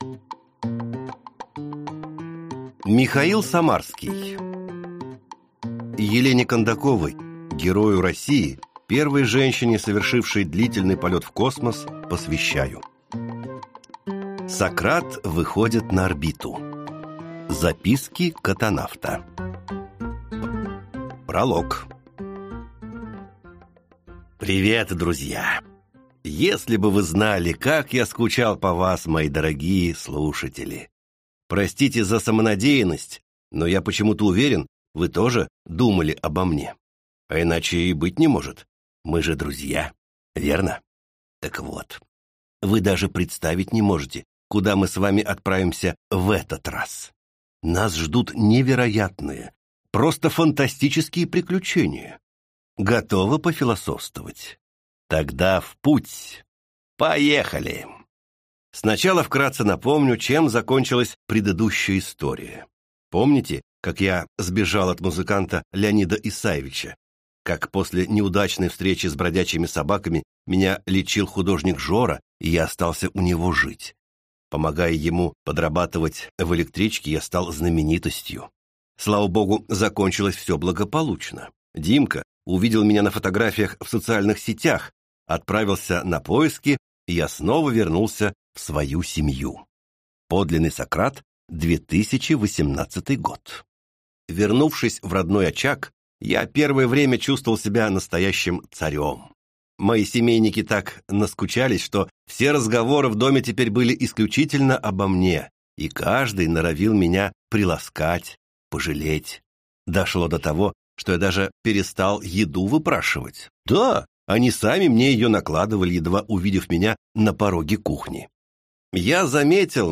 Аудиокнига Кот Сократ выходит на орбиту. Записки котонавта | Библиотека аудиокниг